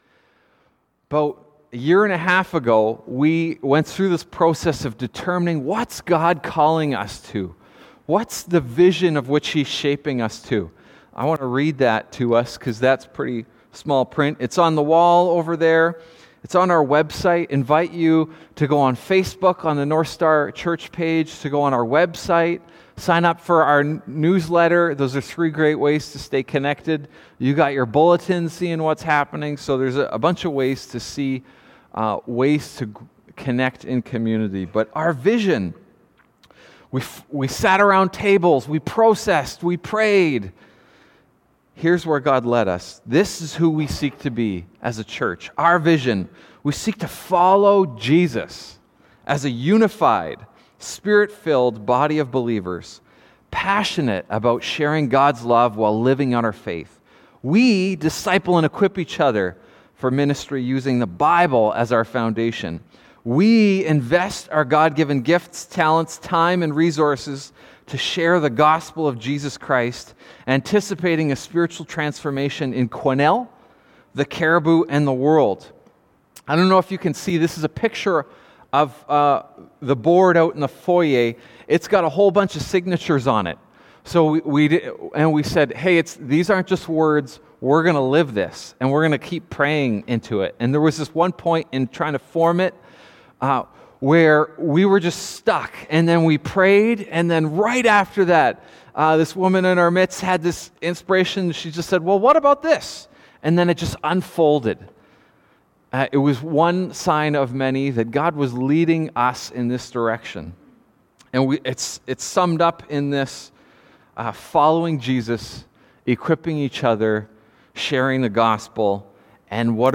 Sermons | Northstar Church